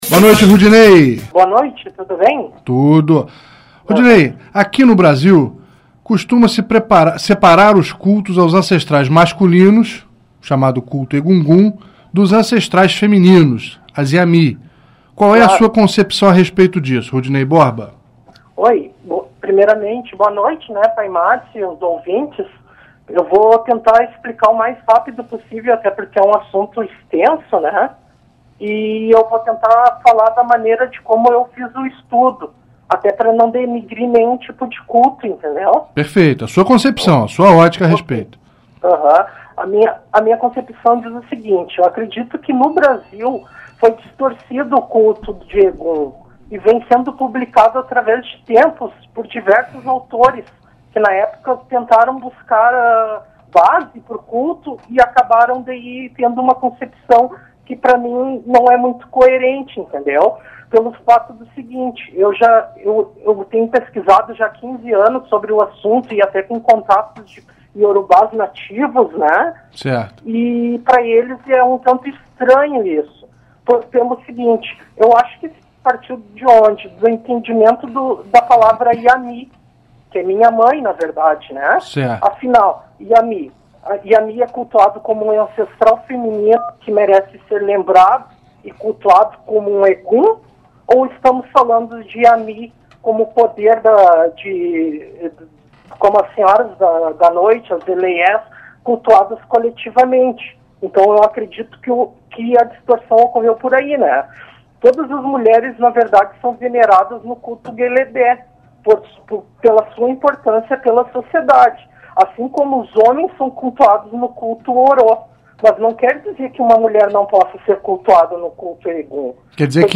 Entrevistas e Debates